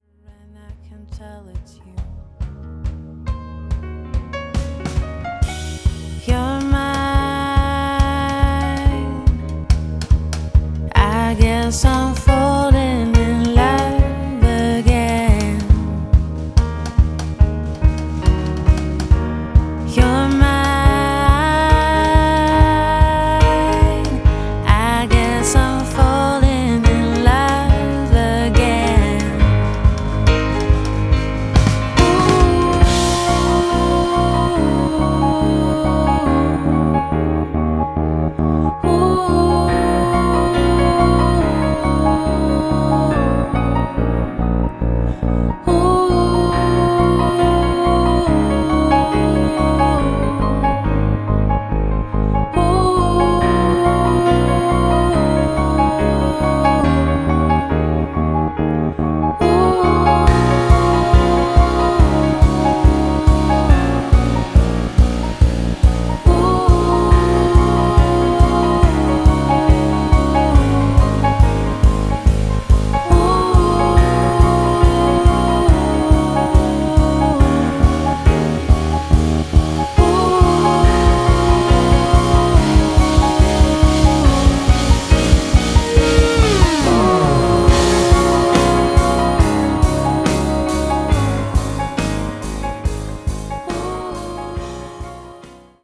lead vocal, keyboard
bass
drums
guitars, keyboard